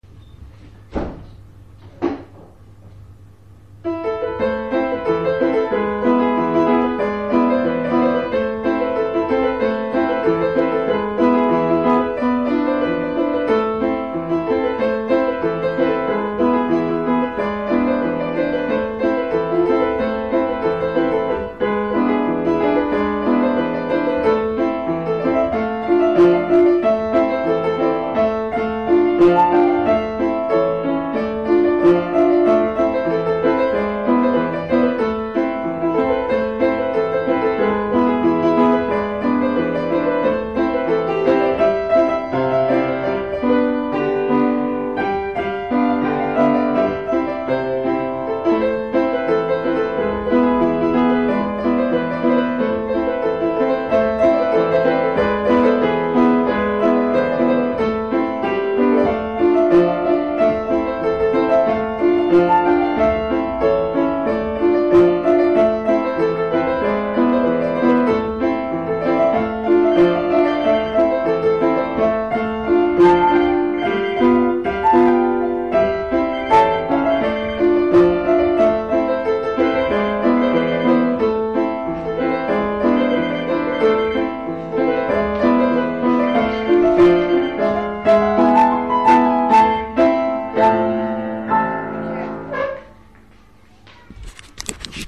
ניגון
אבל בכללי זה נחמד... אם כי לא אהבתי את הקטעי - האטה הלא מובנים....
דווקא ההאטות באמצע עשו את האיכות, ובייחוד הטלפון! הוא משתלב מעולה :)
א. רעשי רקע- מי כתוצאה מההקלטה ב-mp3(?), ומי כתוצאה מגורמים סביבתיים - טלפון, כיסא וכו'
ב. היו מקומות שיצאת מהקצב. גם לפני הטלפון, וגם בזמן הטלפון (שככל הנראה הוא הפריע לך לריכוז)